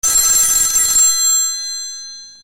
电话铃声 " 铃声
描述：在英国电话上录制的电话铃音
Tag: 回铃 电话 音调 UK